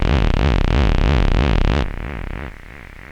• monodelay35 - C - 90.wav
Loudest frequency 473 Hz Recorded with monotron delay and monotron - analogue ribbon synthesizer
monodelay35_-_C_-_90_AJJ.wav